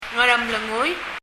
Ngaremlengui　という綴りをどうローマ字読みしても「アルモノグイ」にはならないのですが、ひょっとするとそう聞こえるかも、と３回発音してもらいましたが、「アルモノグイ」とは聞こえませんでした。
発音
もし、ムリヤリ　カナ表記するのでしたら、「(ガ）アラムレ（グ）ゥイ」あたりかな？